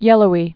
(yĕlō-ē)